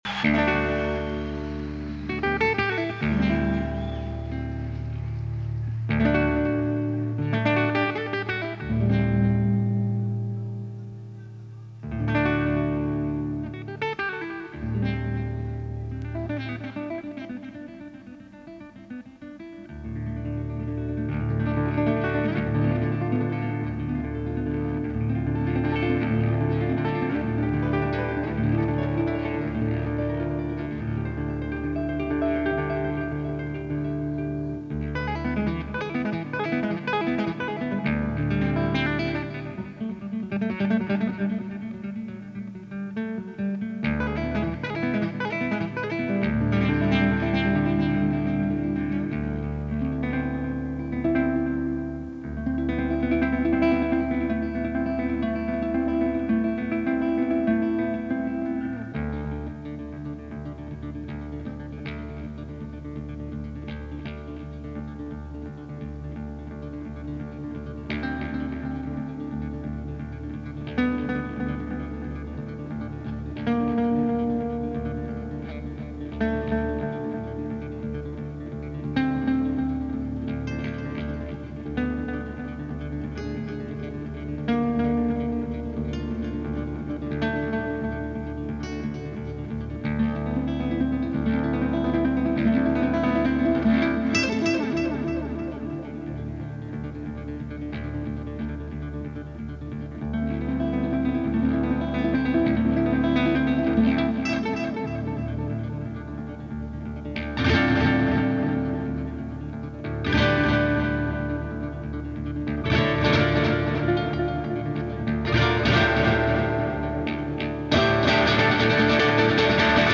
live album